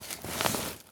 foley_sports_bag_movements_12.wav